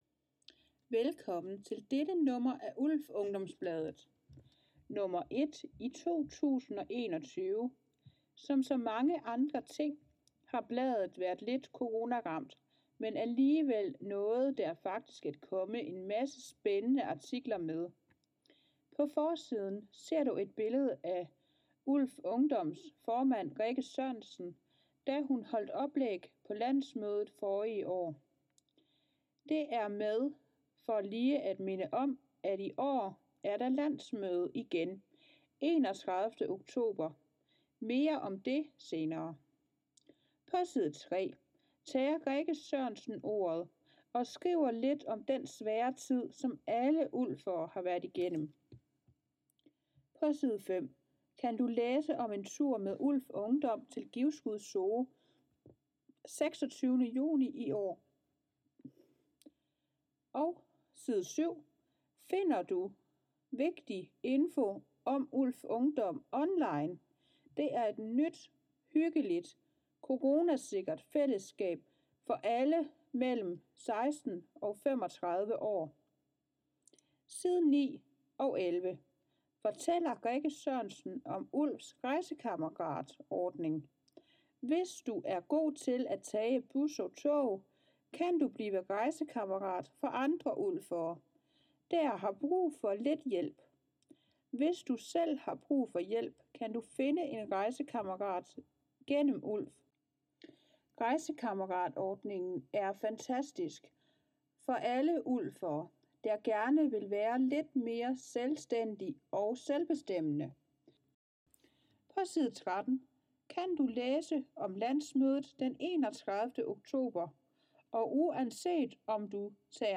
ULF Ungdom nr. 1 – 2021 – Speaket kommer hér: